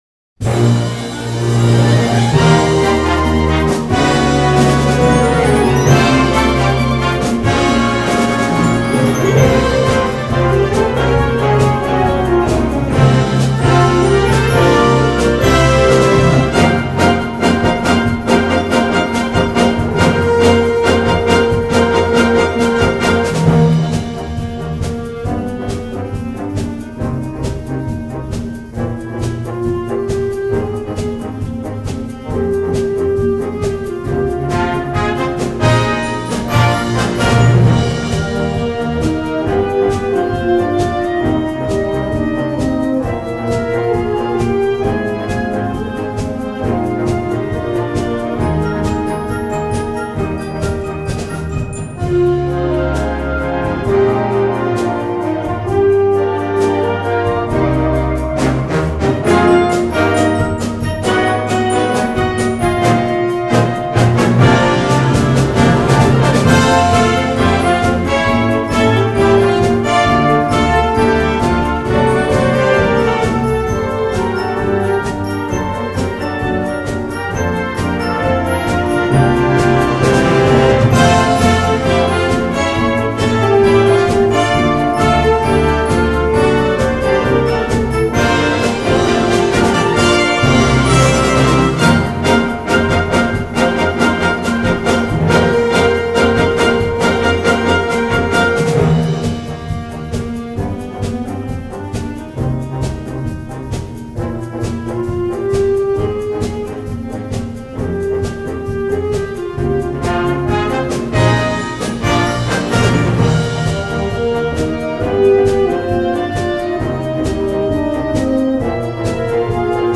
吹奏楽バージョン【４分24秒】